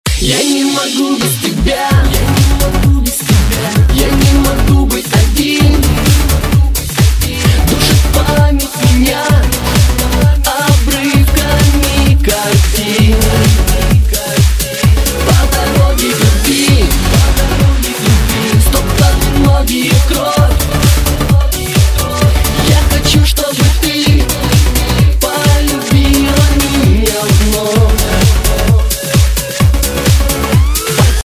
поп
громкие
попса